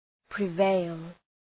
Προφορά
{prı’veıl}